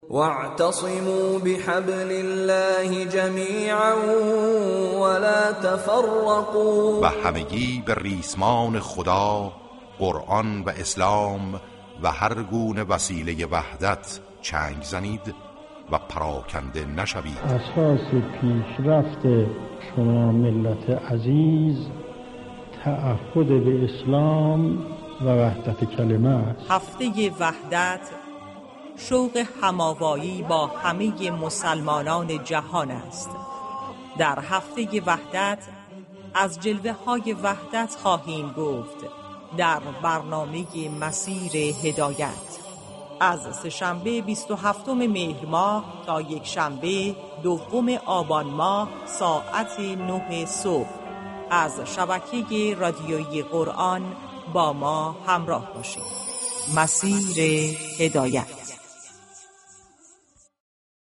گزارش مردمی پیرامون موضوع اختصاصی هر قسمت از برنامه، پخش سخنرانی درخصوص وحدت و نقش وحدت در بین مسلمین ، معرفی كتاب ، آیتم روایت زندگی و ویژگی های پیامبر اكرم (ص) و بخش كارشناسی كه هر روز به یكی از ویژگی های پیامبر (ص) از دو جنبه الگو برداری از این ویژگی ها و نقش آن ها در ایجاد همبستگی در بین مسلمین جهان به آن پرداخته می شود از جمله آیتم های این برنامه می باشد.